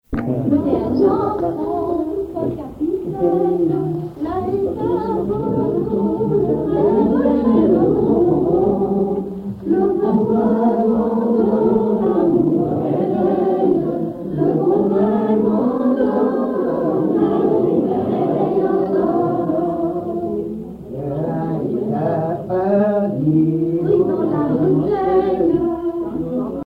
Localisation Cancale (Plus d'informations sur Wikipedia)
Genre laisse
Catégorie Pièce musicale inédite